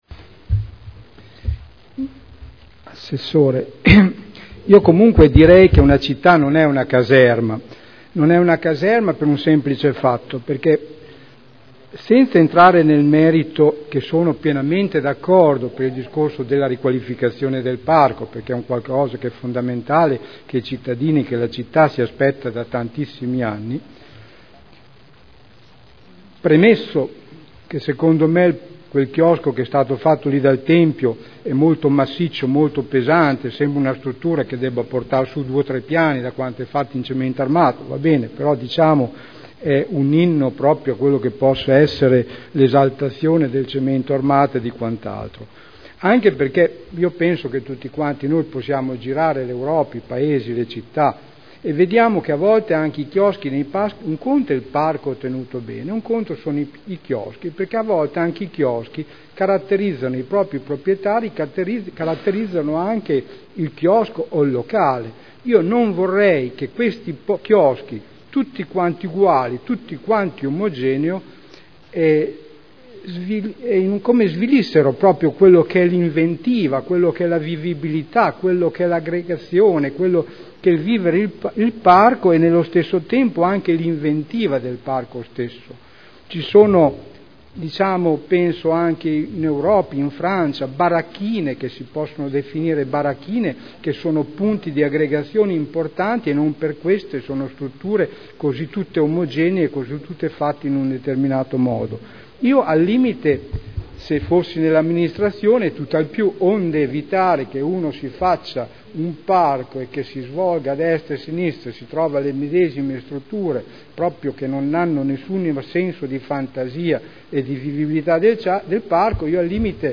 Sergio Celloni — Sito Audio Consiglio Comunale
Seduta del 12/09/2011.